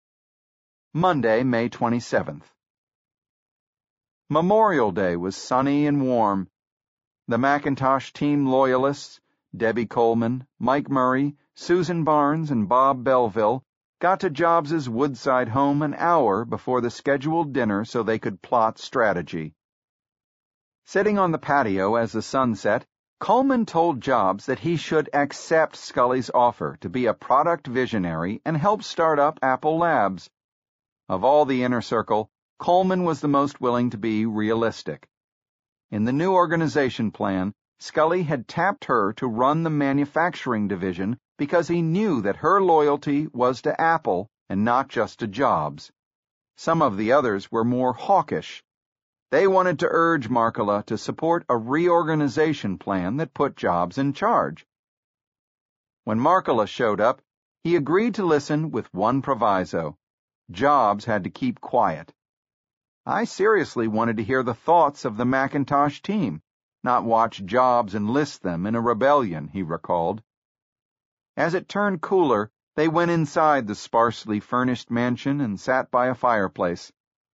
在线英语听力室乔布斯传 第200期:五月的关键七天(9)的听力文件下载,《乔布斯传》双语有声读物栏目，通过英语音频MP3和中英双语字幕，来帮助英语学习者提高英语听说能力。
本栏目纯正的英语发音，以及完整的传记内容，详细描述了乔布斯的一生，是学习英语的必备材料。